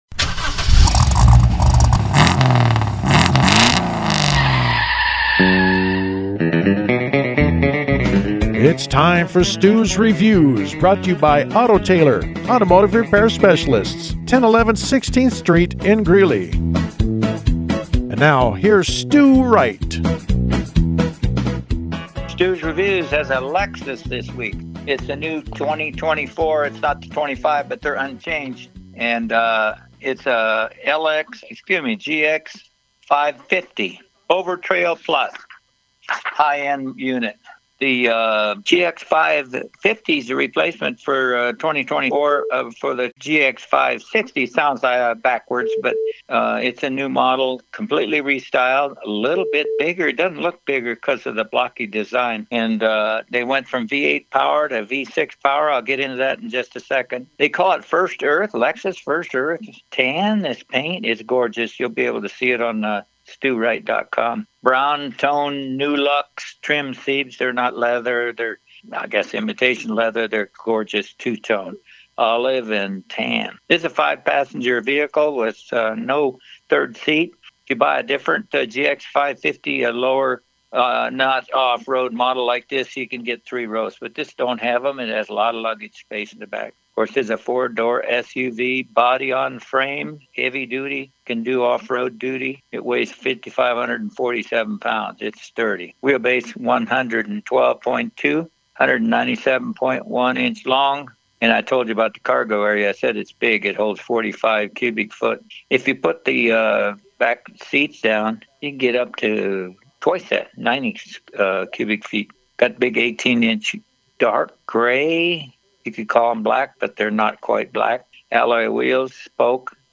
The Lexus review was broadcast on Pirate Radio FM 104.7 in Greeley: